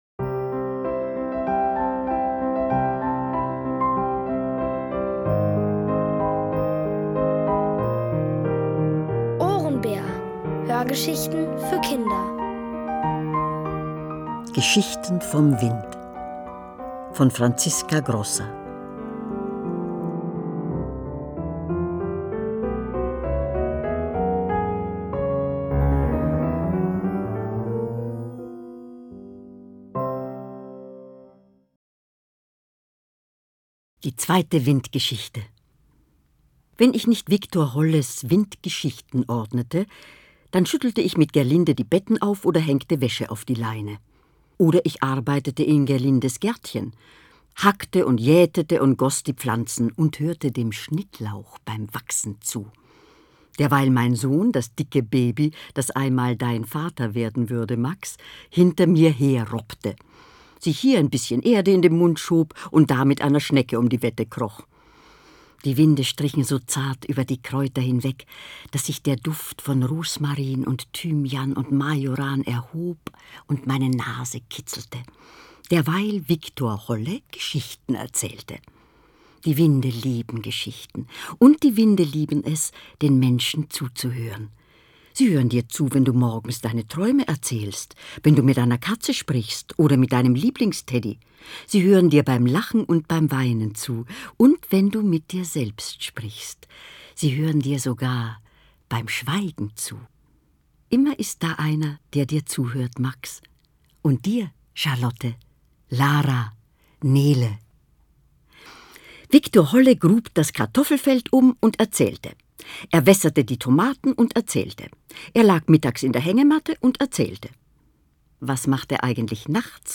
Von Autoren extra für die Reihe geschrieben und von bekannten Schauspielern gelesen.
liest: Elfriede Irrall.